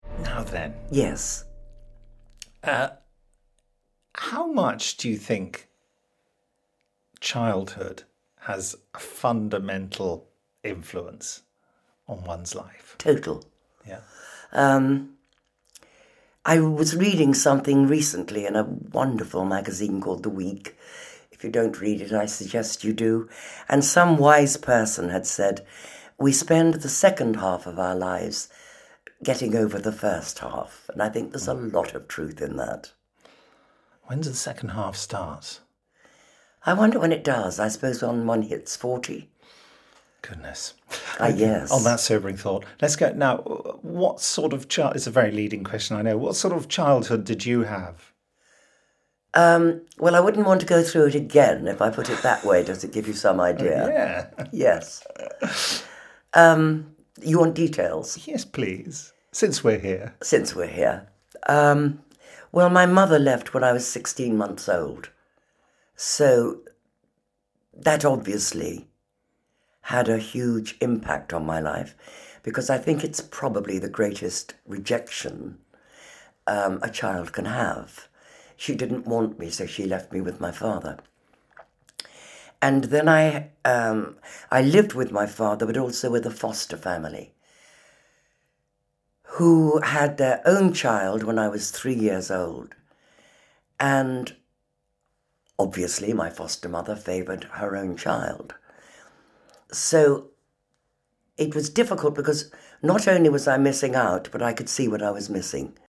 But these are just two roles in a distinguished career, and a fascinating life. She gives an uncompromising interview to Nicholas Briggs.